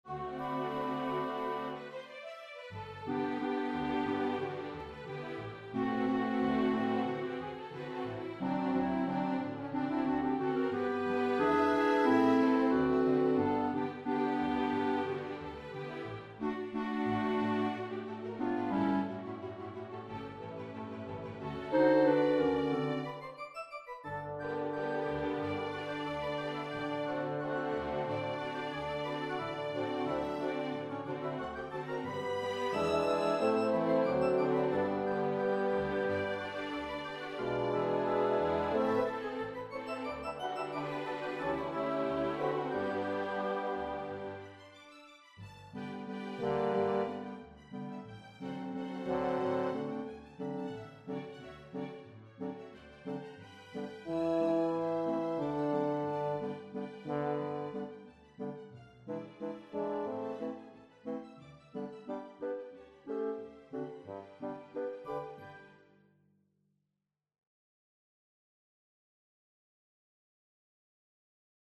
Daughters, Sons and Chorus